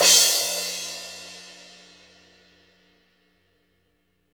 Index of /90_sSampleCDs/Roland - Rhythm Section/CYM_Crashes 1/CYM_Crash menu
CYM 15 DRK0L.wav